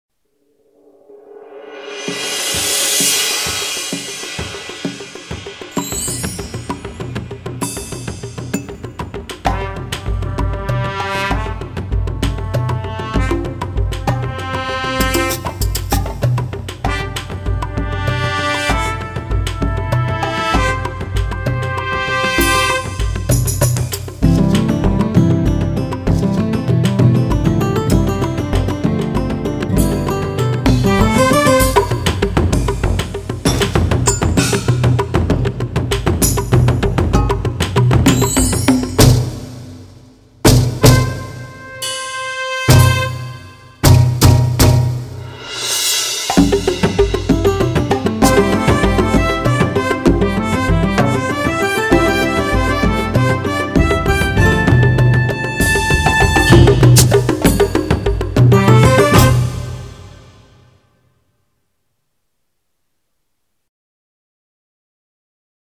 Tema musical que se escucha en el mundial en cada ronda para avisar a los jugadores que estamos comenzando.